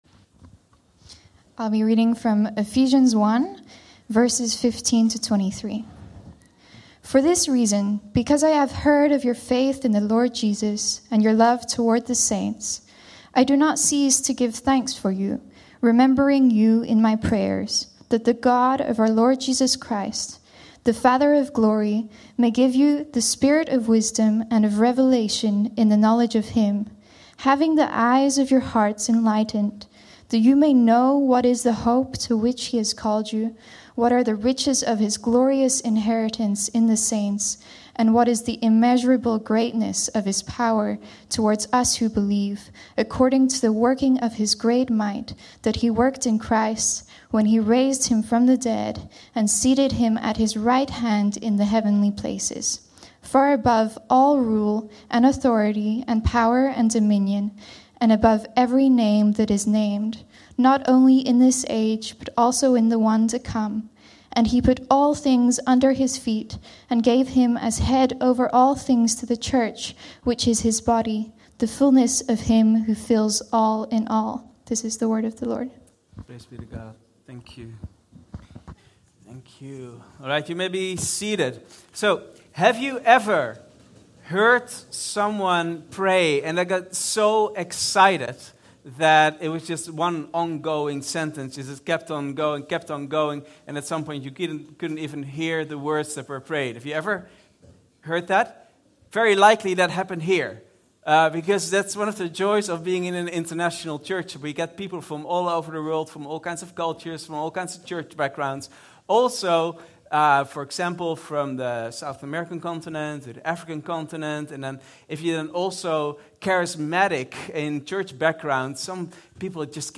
Vineyard Groningen Sermons Identity | Part 2 | Where Do I Belong?